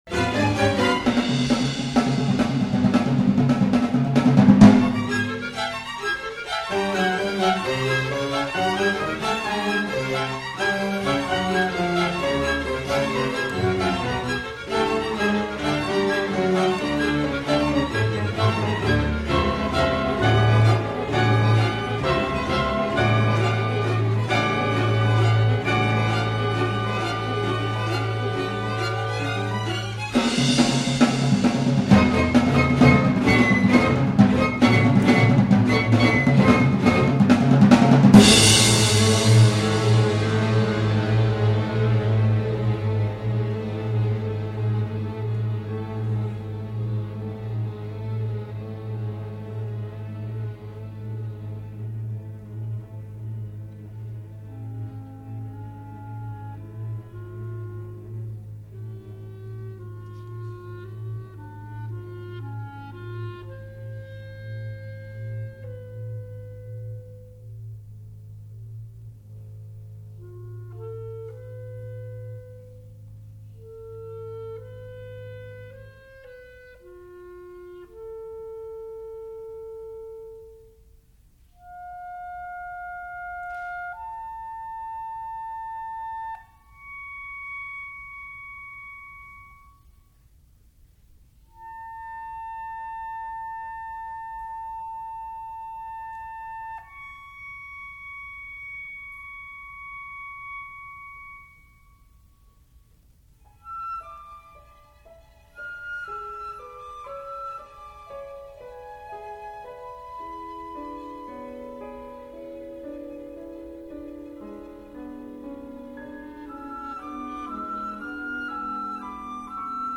for flute, clarinet, bassoon, strings, piano and percussion
An excerpt from a performance of Mysic Illusion can be heard at this link:
Musically, this piece begins with a rather high and light ostinato of piano and vibraphone over which is placed a melody for solo violin and viola. Eventually, low sounds from the bassoon and cello interrupt and initiate a series of contrasting (quarter-note) triplet figures that increase tension and a feeling of conflict. The tempo increases and the piece develops in an aggressive way. After a final burst of drums and tutti syncopations, the contrasting triplet idea comes back as dense layers of sound that gradually fade, revealing a simple clarinet solo.